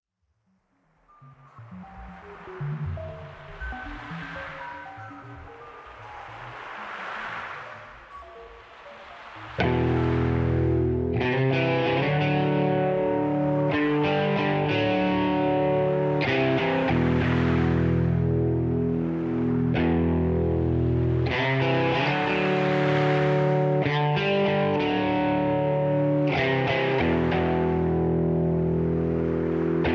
backing tracks, karaoke, rock